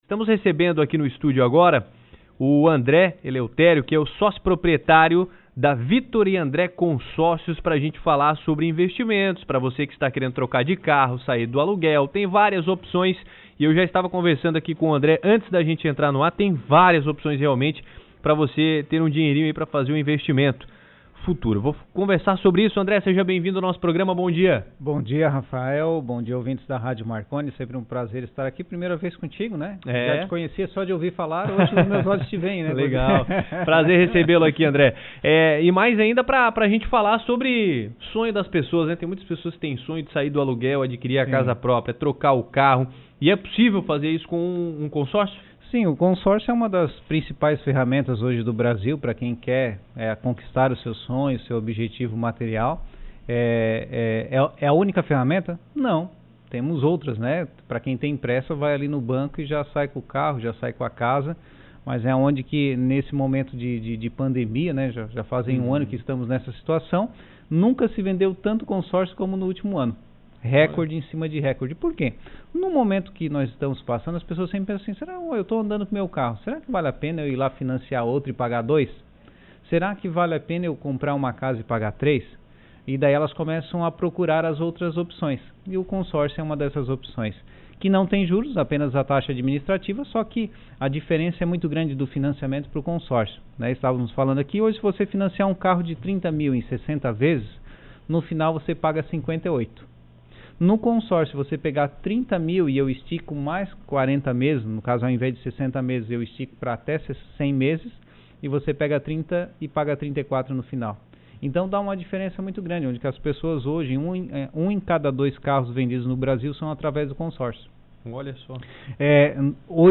ENTREVISTA-01.mp3